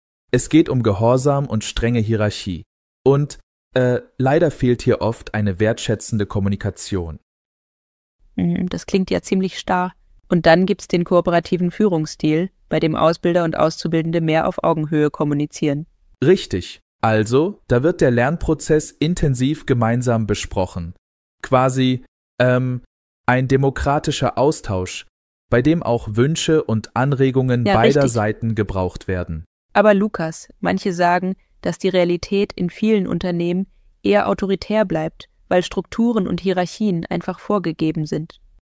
Folge 6 der KI-basierten Podcast-Reihe „Lektion k„.